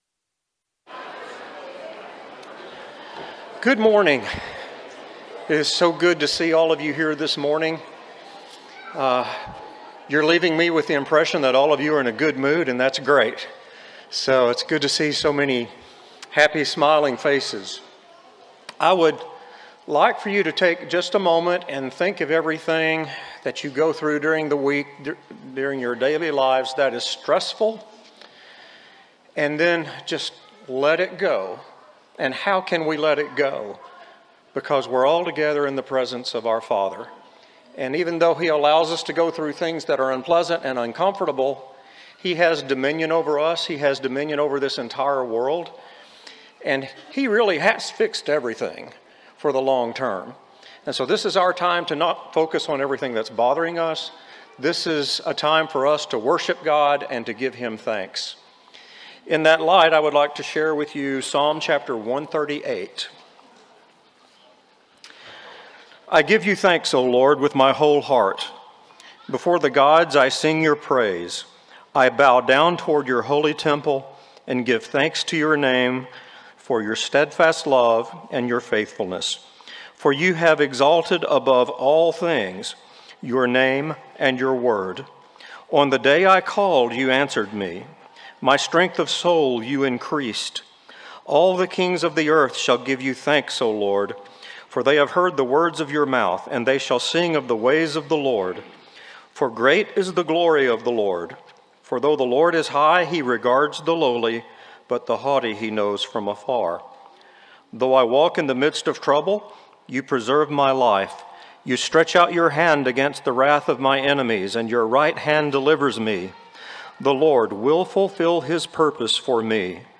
John 13:35, English Standard Version Series: Sunday AM Service